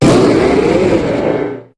Cri de Méga-Latios dans Pokémon HOME.
Cri_0381_Méga_HOME.ogg